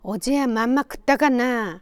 Aizu Dialect Database
Final intonation: Falling
Location: Aizumisatomachi/会津美里町
Sex: Female